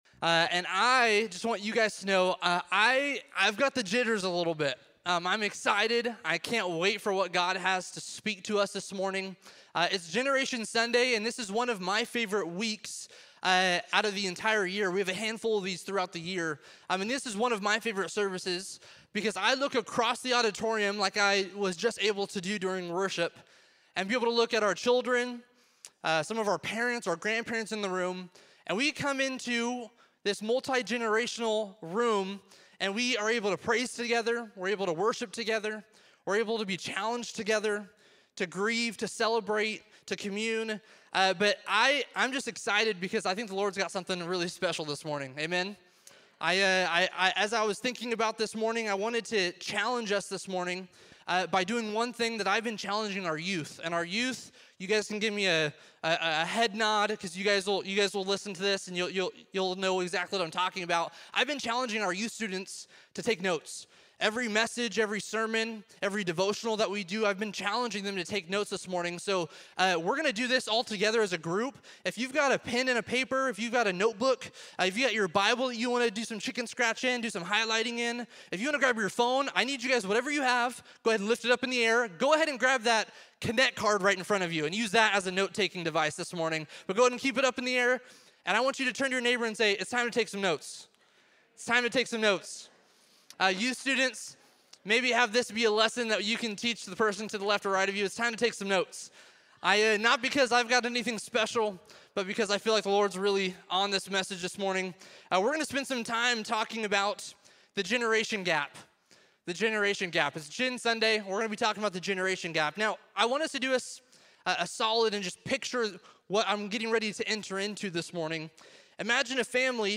In this sermon, we’re invited into a bigger vision of faith that isn’t meant to be lived alone, but carried across generations—where each age group has a role in God’s story. The message highlights the beauty of honoring those who have gone before us, recognizing their faithfulness, wisdom, and sacrifices as a foundation we stand on.